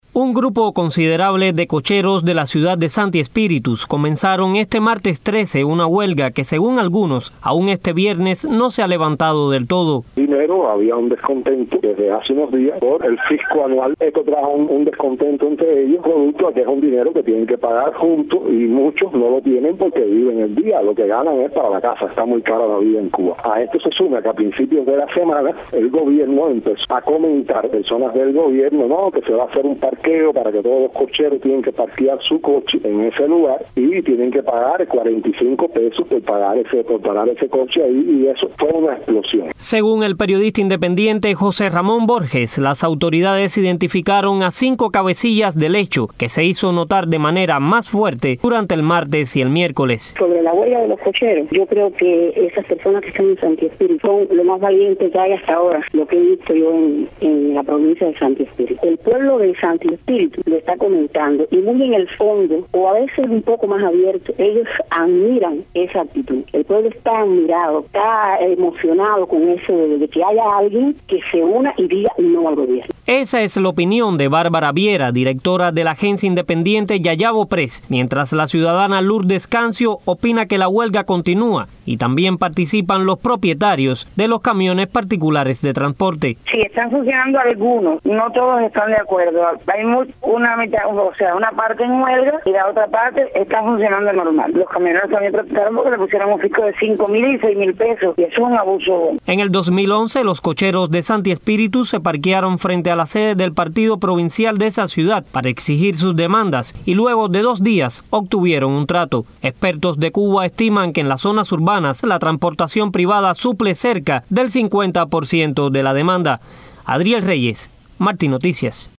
entrevistó a varios testigos del hecho.